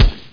MG_SHOT2.mp3